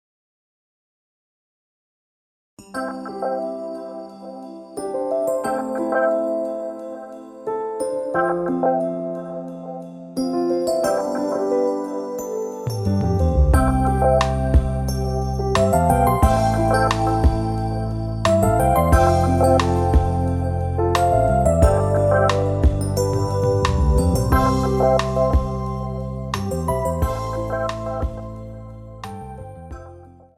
Features a rhythm and some famous synth sounds.
• Type : Instrumental
• Bpm : Andante
• Genre : Rock / Jazz / R&B